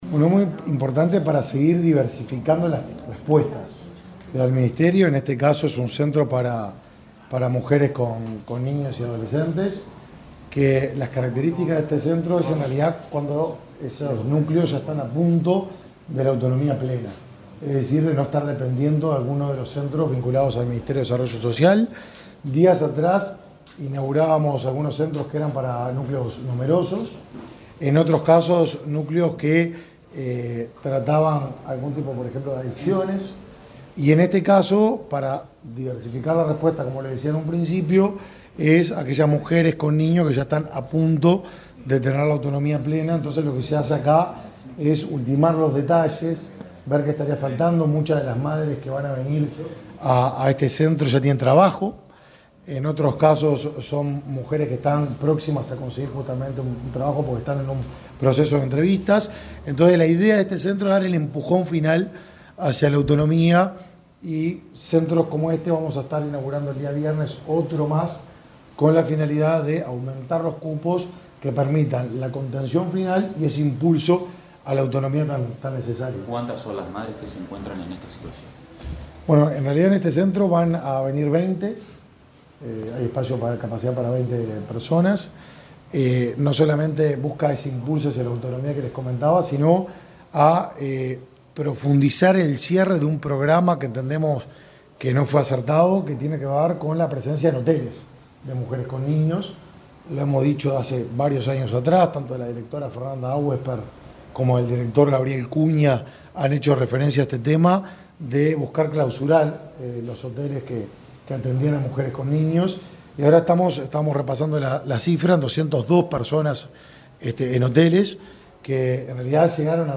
Declaraciones del ministro de Desarrollo Social, Martín Lema
Declaraciones del ministro de Desarrollo Social, Martín Lema 14/03/2023 Compartir Facebook X Copiar enlace WhatsApp LinkedIn El Ministerio de Desarrollo Social (Mides) abrió en Montevideo un nuevo centro de 24 horas para familias monoparentales con jefatura femenina y niños o adolescentes a cargo. El titular de la cartera, Martín Lema, visitó el local y dialogó con la prensa.